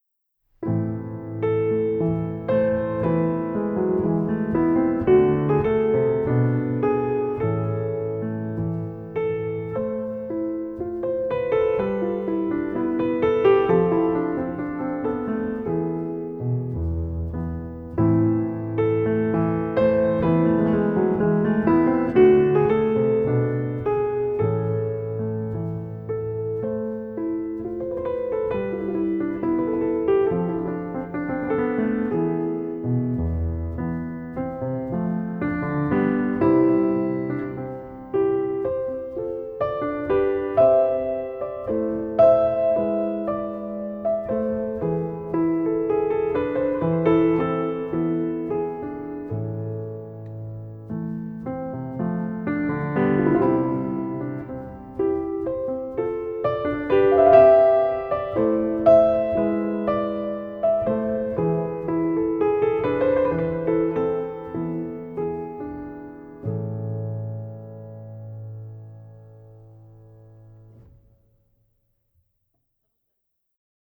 Prelude
William Byrd’s Pavana “The Earl of Salisbury” performed by pianist